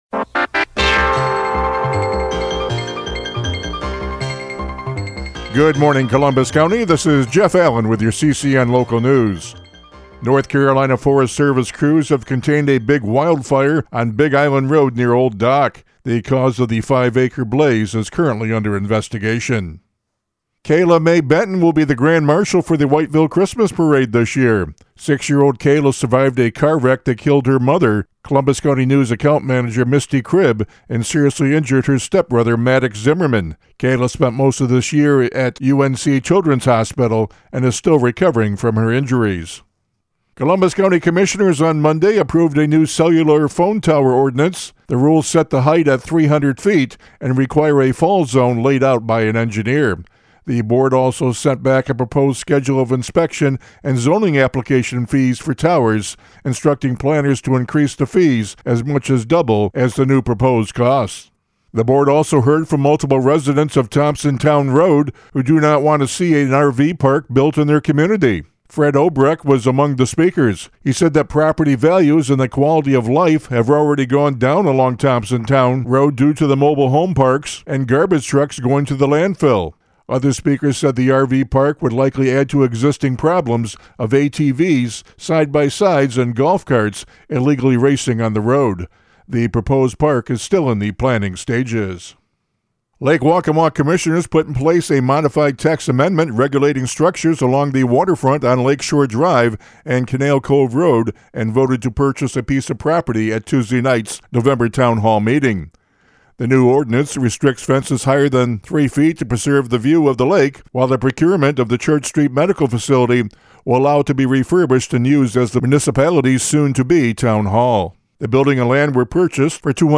CCN Radio News — Morning Report for November 19, 2025